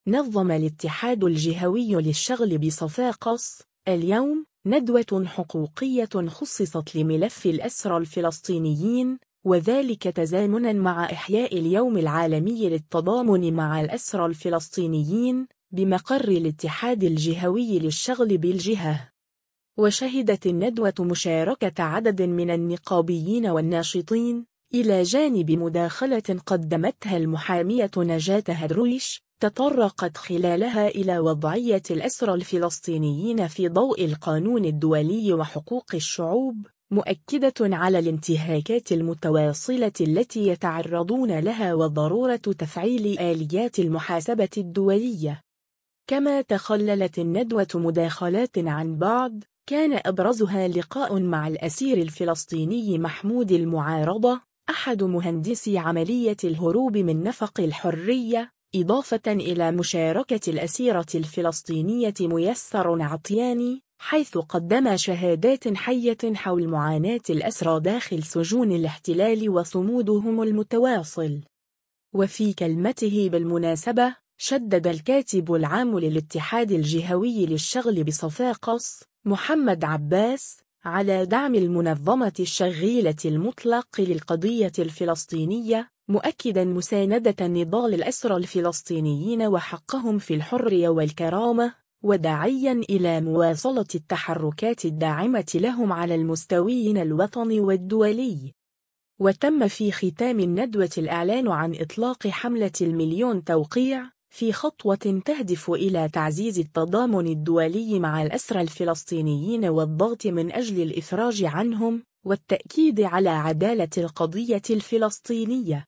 نظم الاتحاد الجهوي للشغل بصفاقس، اليوم، ندوة حقوقية خُصّصت لملف الأسرى الفلسطينيين، وذلك تزامنًا مع إحياء اليوم العالمي للتضامن مع الأسرى الفلسطينيين، بمقر الاتحاد الجهوي للشغل بالجهة.